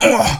PlayerHurt4.wav